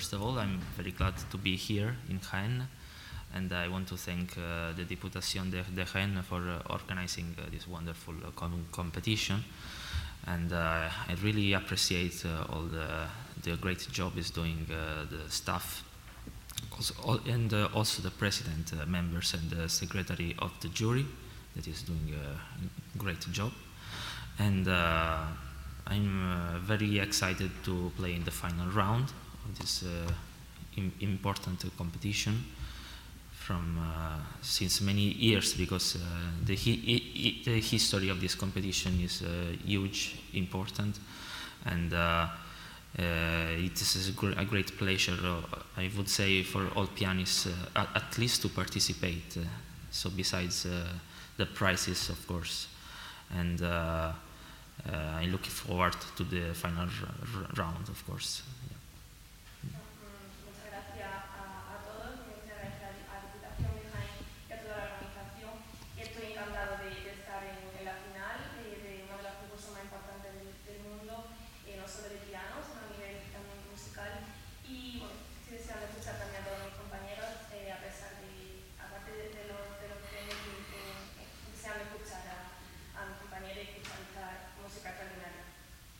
Declaraciones en audio de los finalistas